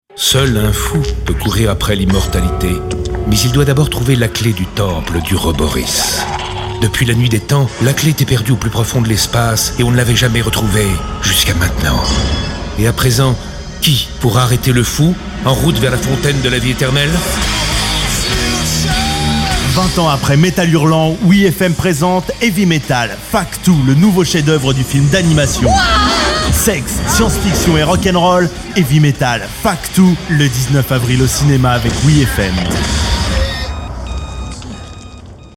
Publicité film d'animation